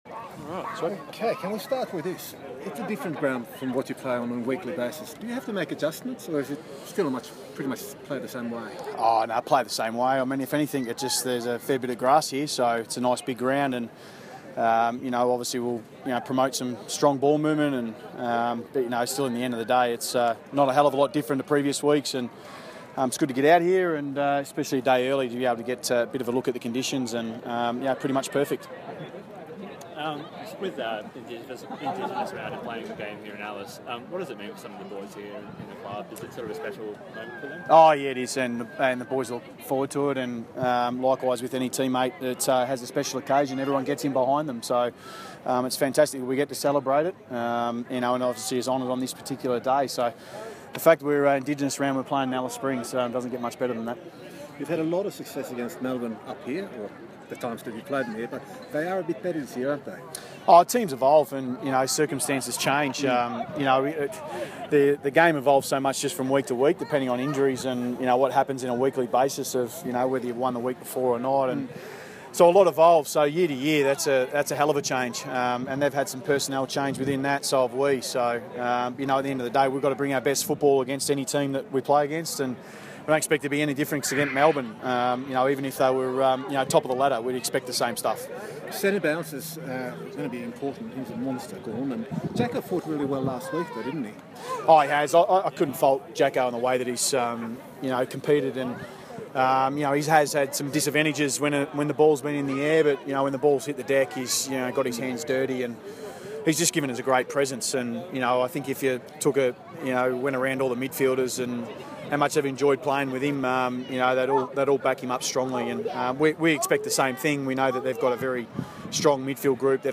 Michael Voss Press Conference - Friday, 27 May, 2016
MIchael Voss talks with media at Alice Springs' Traeger Park after Port Adelaide's Captain's Run.